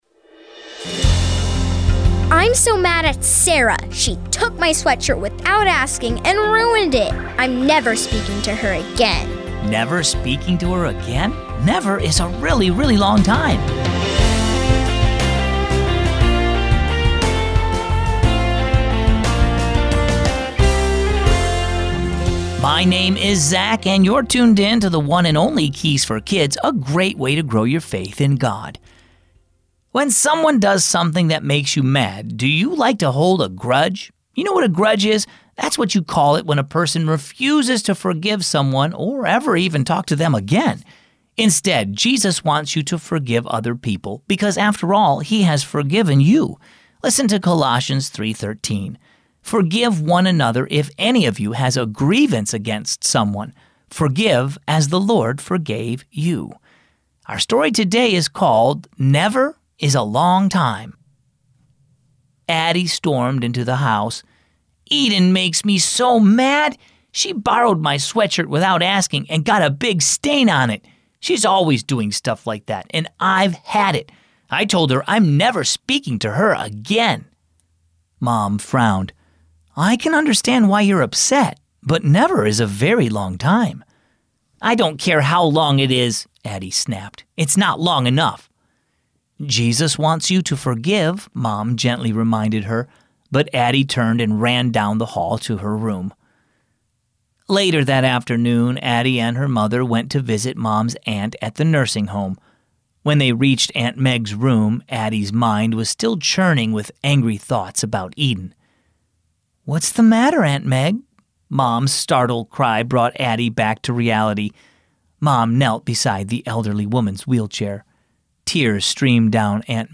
Keys for Kids - daily devotions and Bible stories for kids and families